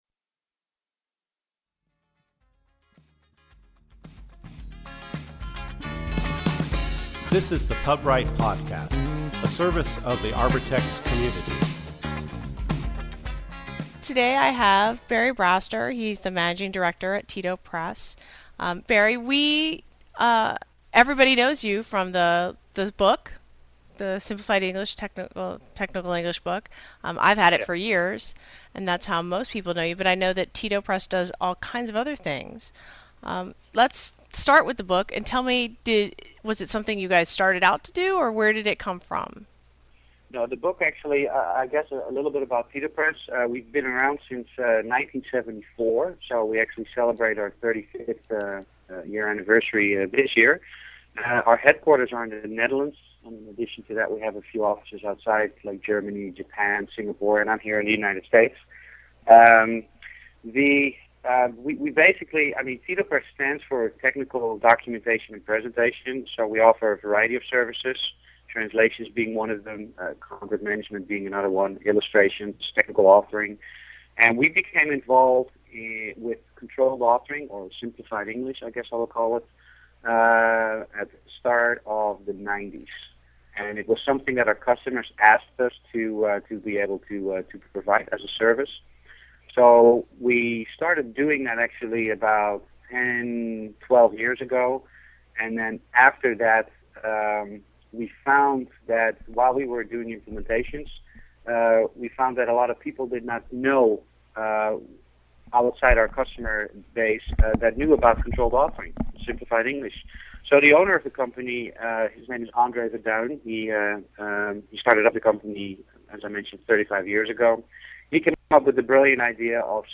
This week's podcast is an interview